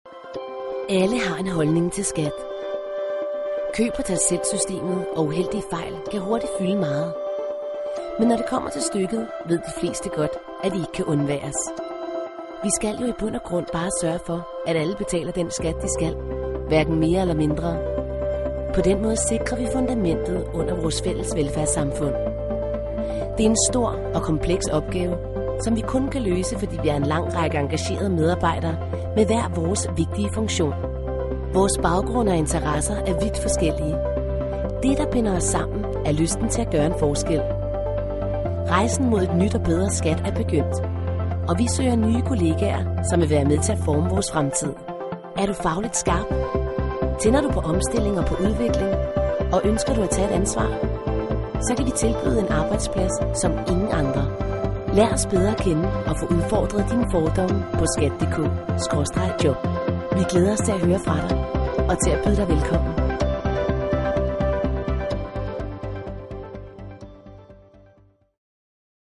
DA MB EL 01 eLearning/Training Female Danish